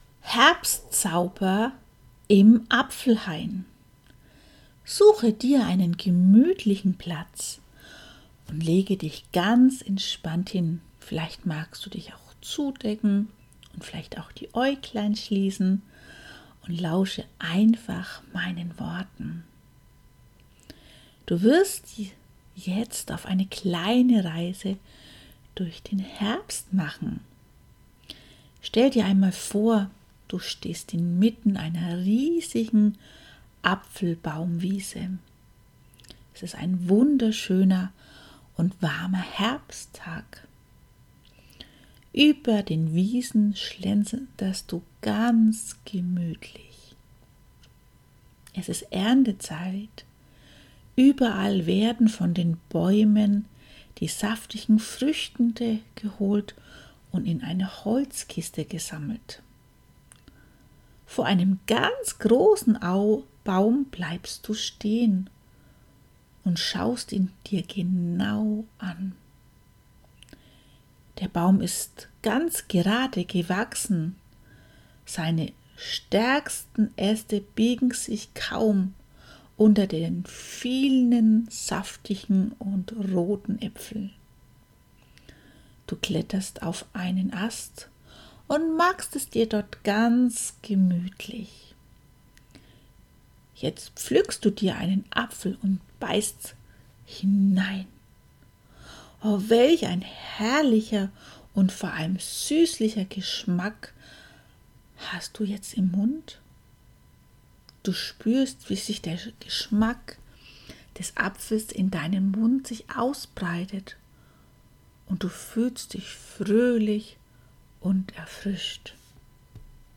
Herbstzauber im Apfelhain: Eine Entspannungsreise für Dich und Deine Haut